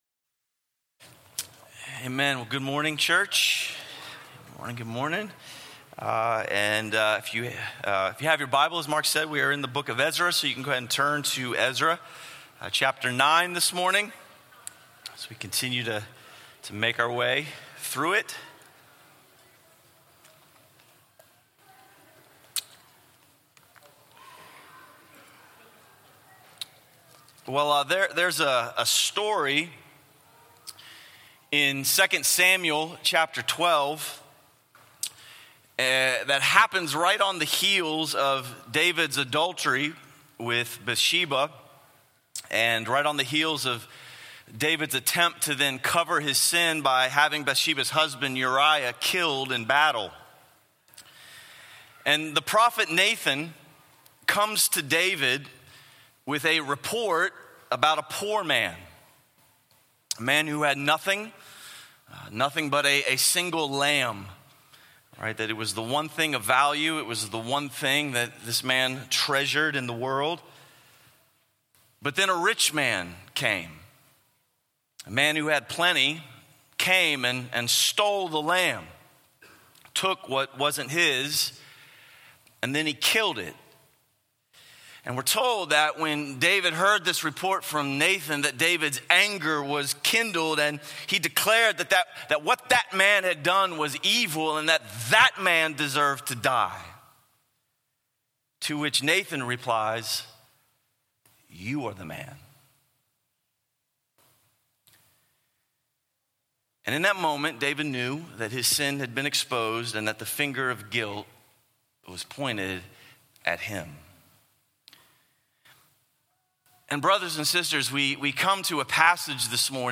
A sermon series through the books of Ezra and Nehemiah.